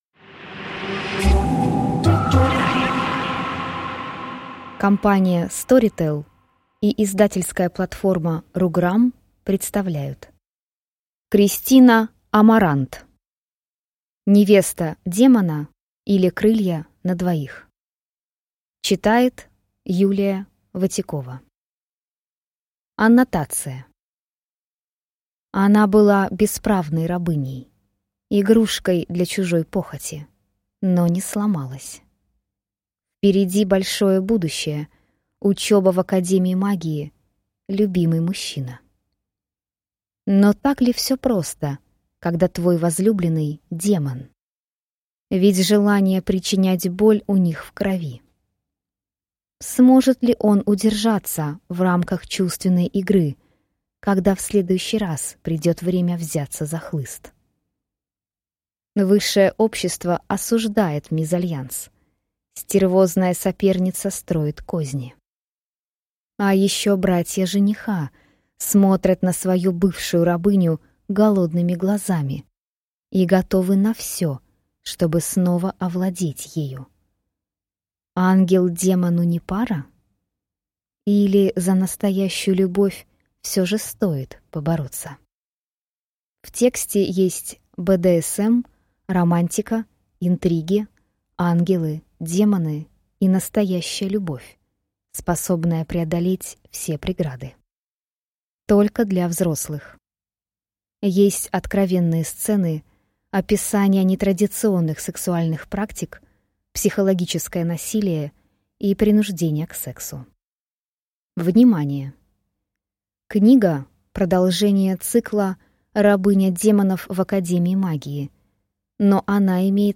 Аудиокнига Невеста демона, или Крылья для двоих | Библиотека аудиокниг